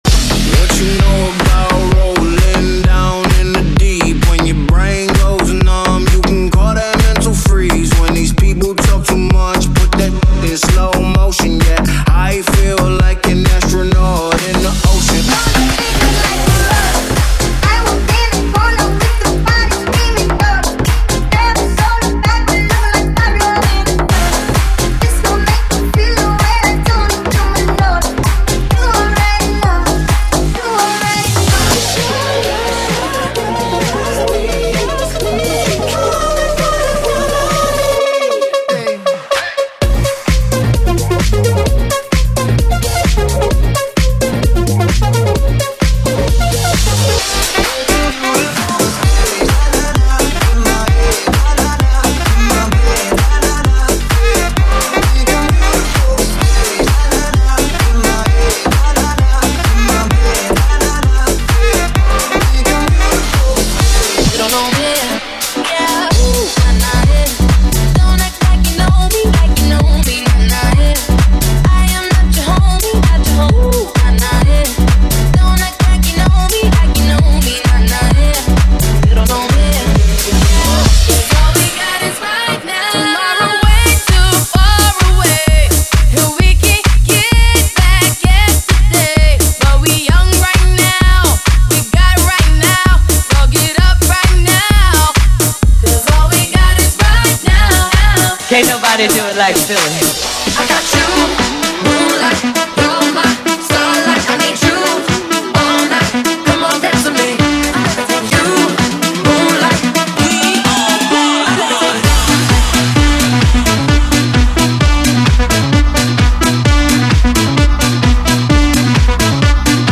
BPM: 130 (56:00)
Format: 32COUNT
New Radio, Classics & EDM.